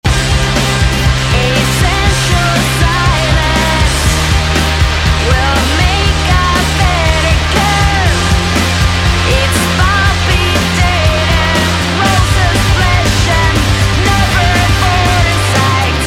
batería
guitarra